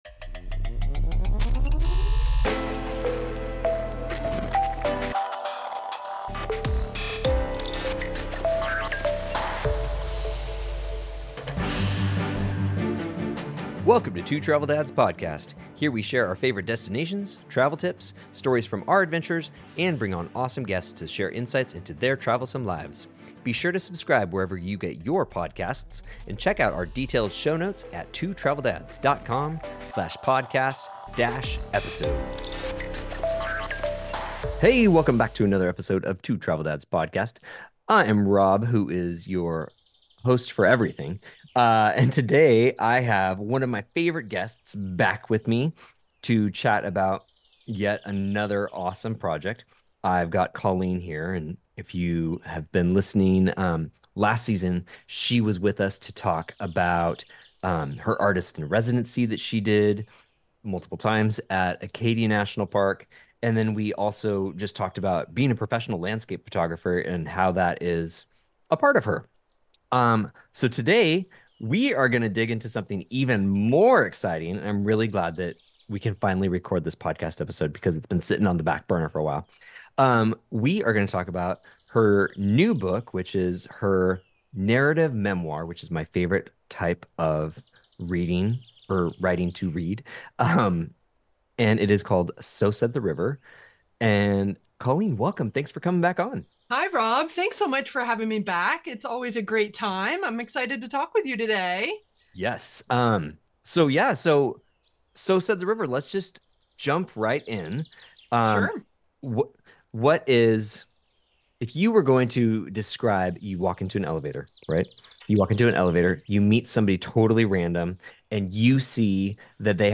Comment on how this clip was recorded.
As a writer I get excited to talk to other writers about their work.